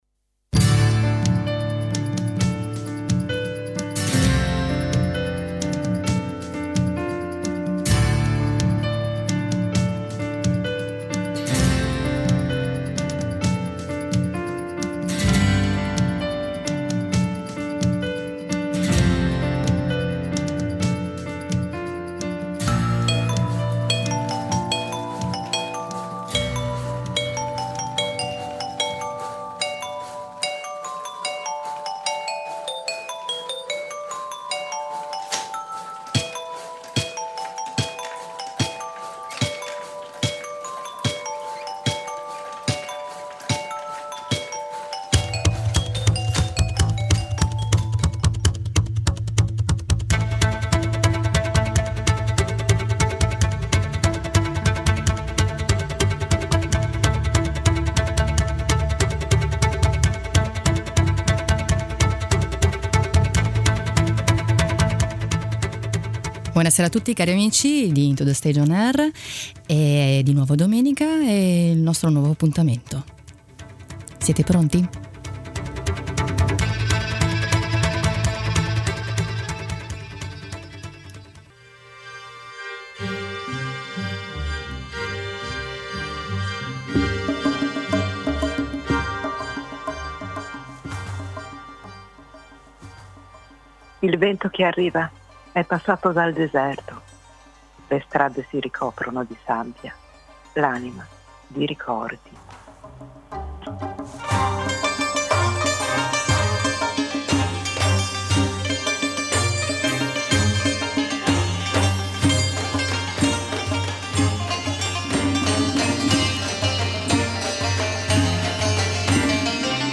In collegamento telefonico
In studio